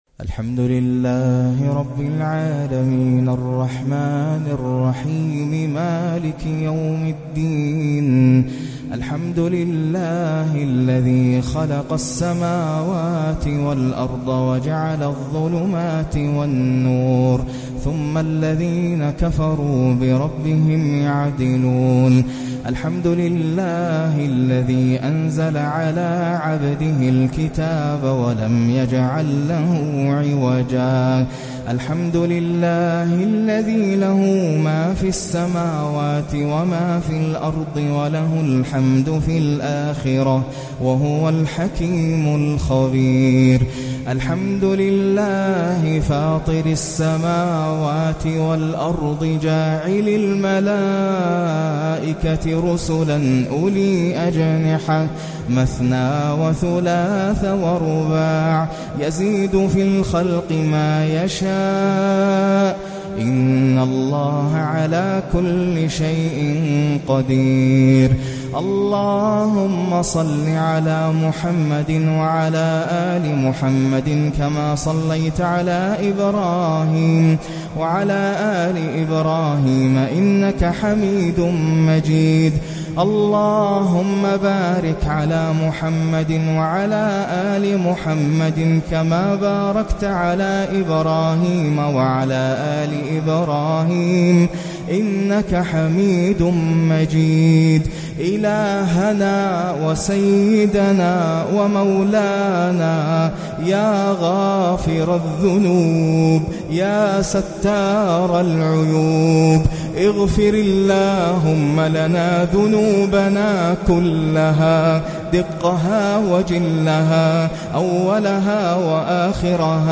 فالآن من عذابك من يستنقذنا قنوت الليلة 12 للشيخ ناصر القطامي رمضان 1439
أدعية ومناجاة